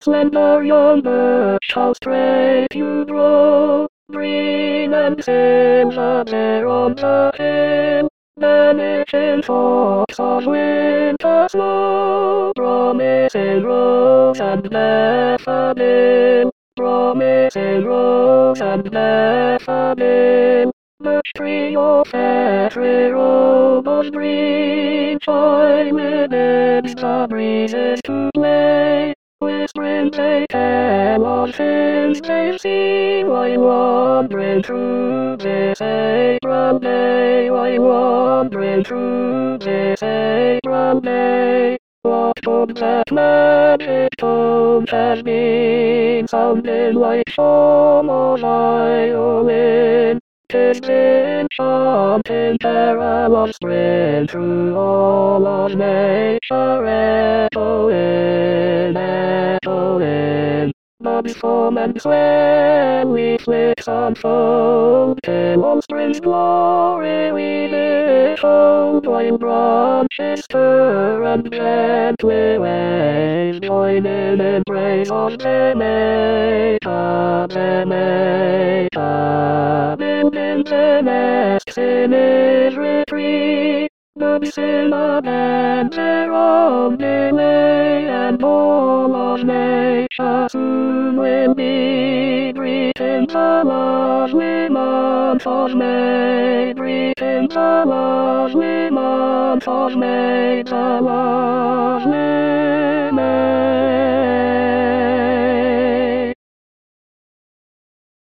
Tenor Tenor 2